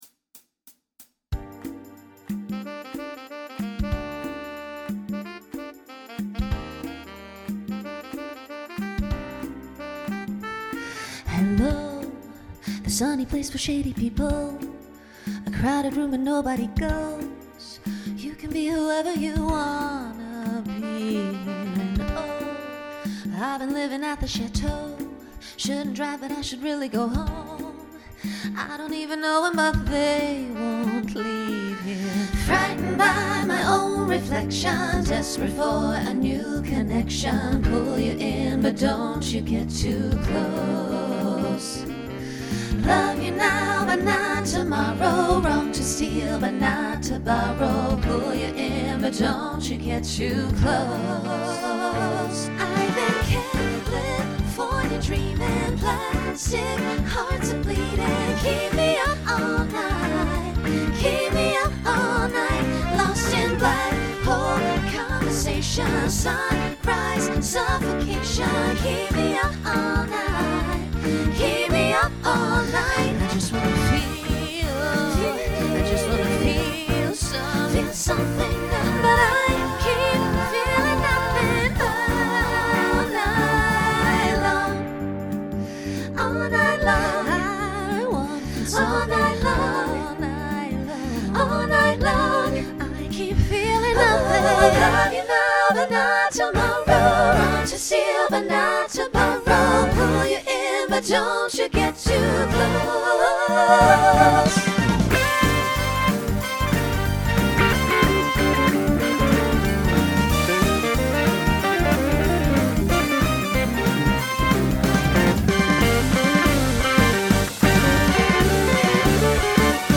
Genre Rock , Swing/Jazz
Solo Feature Voicing SSA